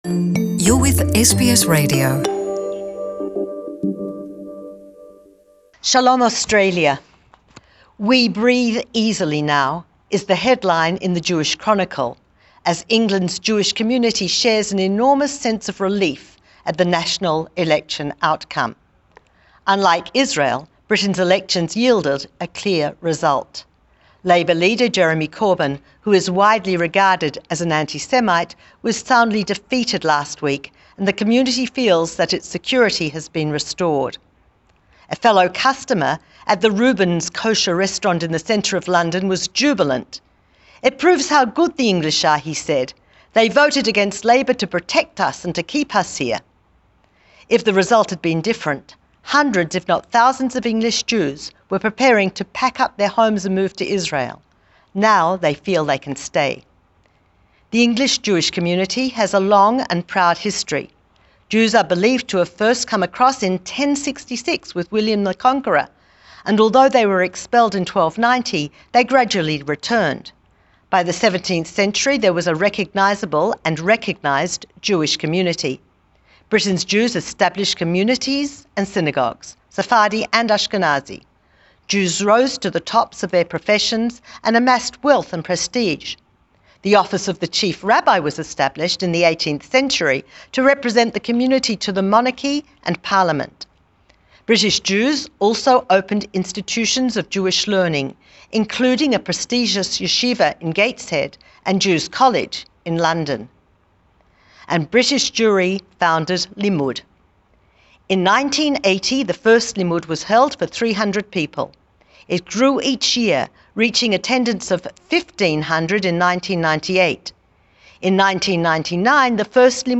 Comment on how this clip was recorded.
A special news report from Limmud, in the United Kingdom.